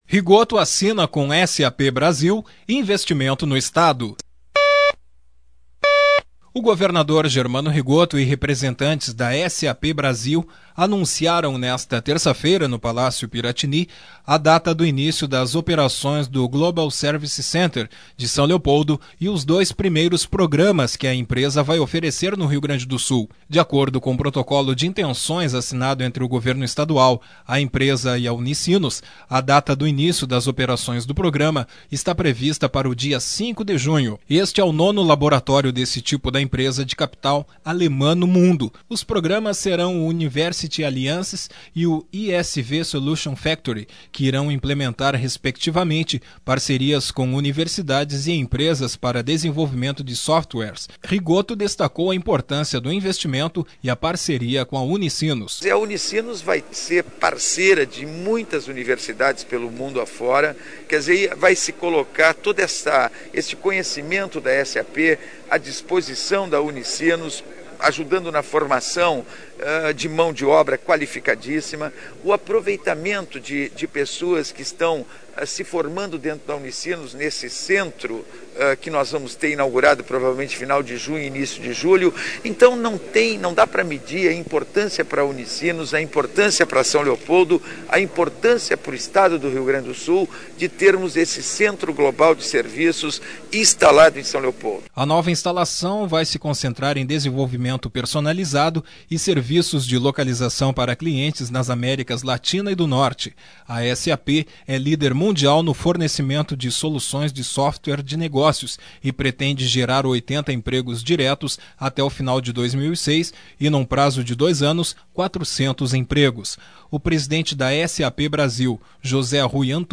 O governador Germano Rigotto e representantes da SAP Brasil anunciaram, nesta 3ª feira, no Palácio Piratini, a data do início das operações do Global Service Center de São Leopoldo e os dois primeiros programas que a empresa vai oferecer no Rio Grande do